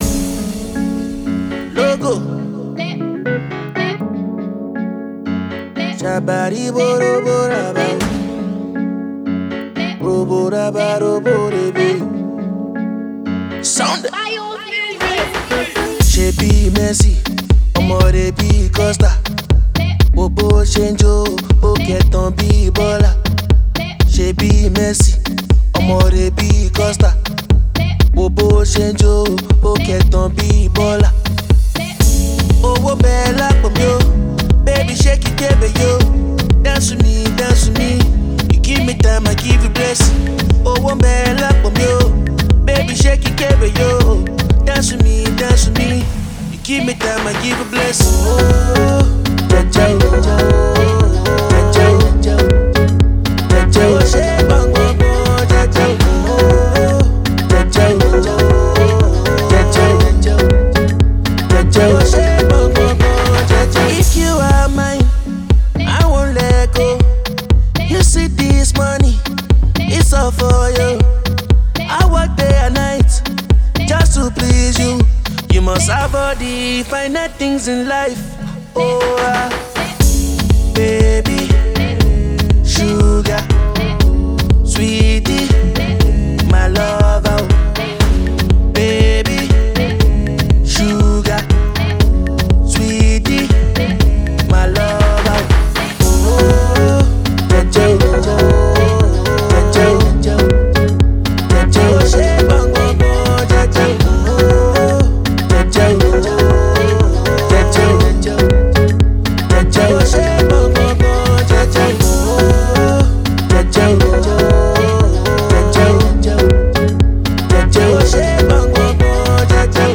summer banger